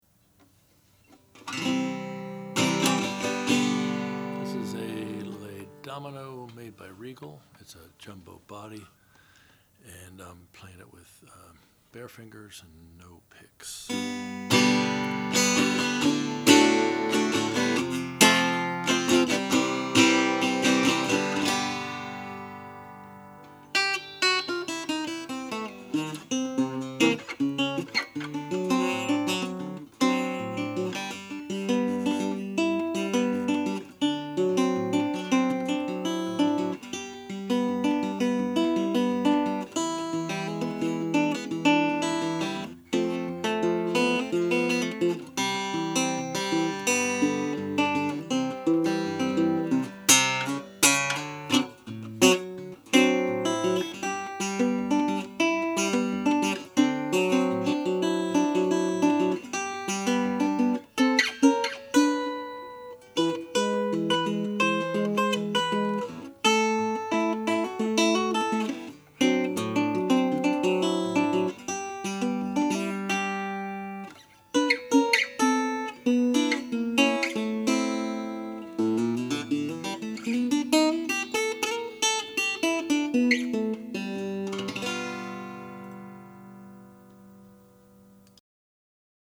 It's a jumbo body, 14-fret, roundhole archtop guitar with a slotted head stock.
The top is pressed solid spruce with a round sound hole.
Strung with 11-52s, with its smooth action, the guitar plays easily and produces a really full tone; deep bass and sparkly mids and highs with an overall arch top timbre.